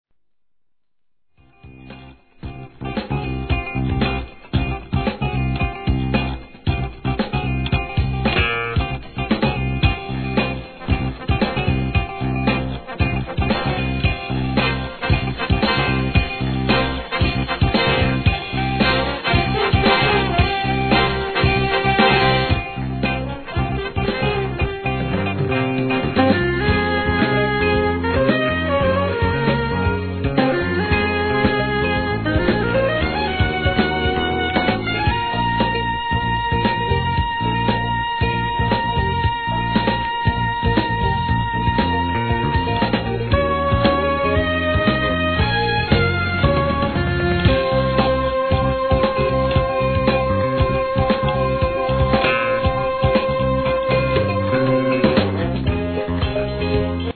1. SOUL/FUNK/etc...